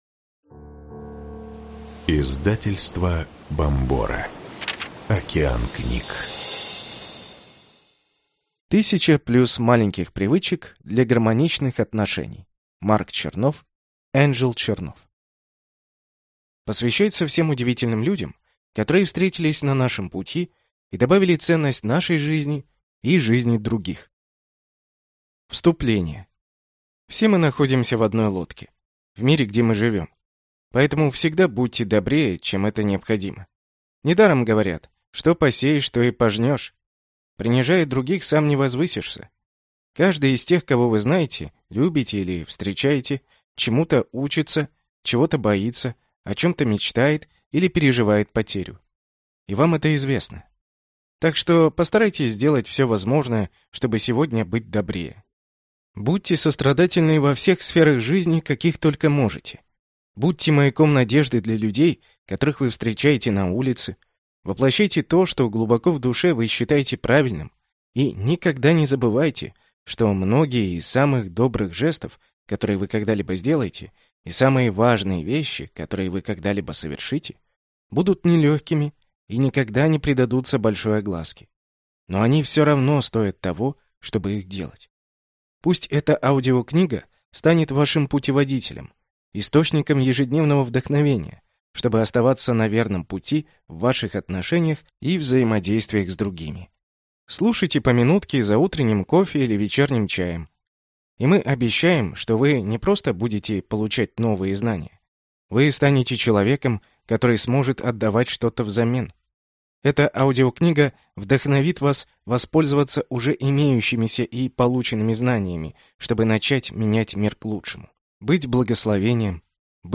Аудиокнига 1000+ маленьких привычек для гармоничных отношений | Библиотека аудиокниг